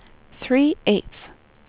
number_8.wav